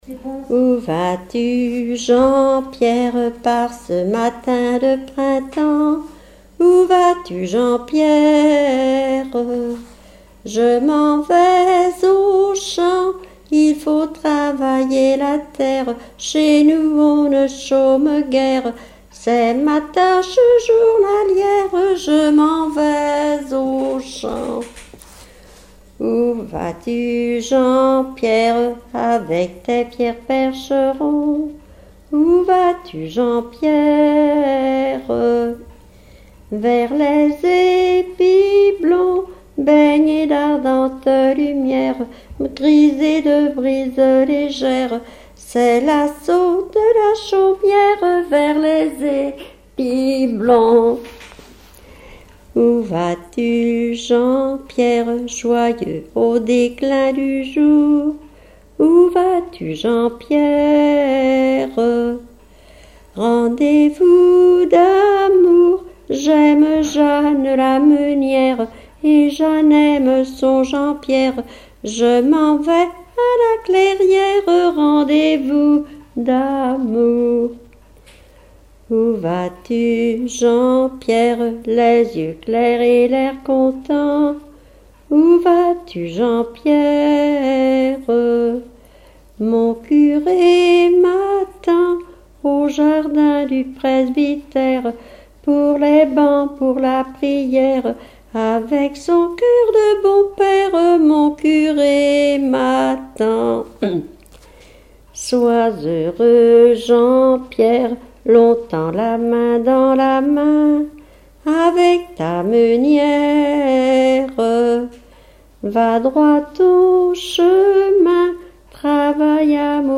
Genre dialogue
Pièce musicale inédite